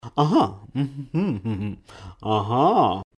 Звуки согласия «ага»
Звуки, на которых люди во время разговора соглашаются и говорят «ага», разные варианты для монтажа.
1. Мужчина слушает, узнаёт что-то и агакает
Zvuk-aga-muj-interes.mp3